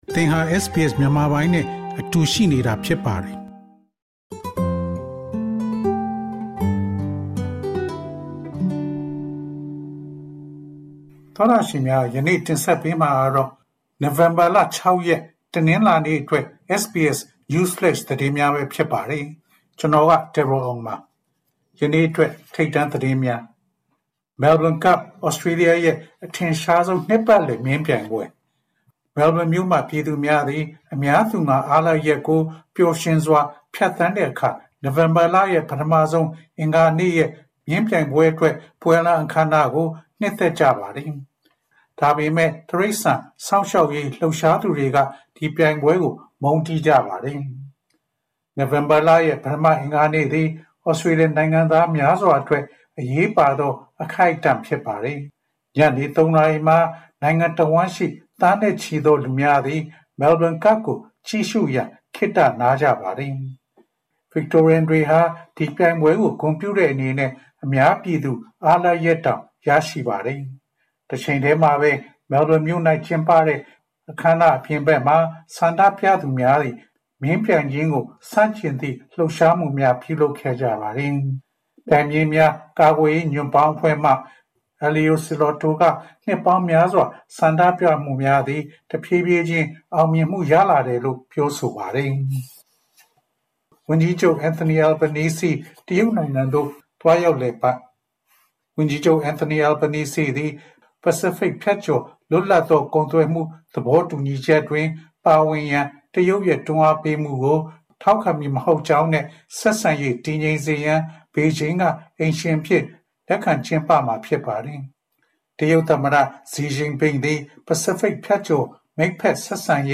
Nov 06 SBS Burmese News Flash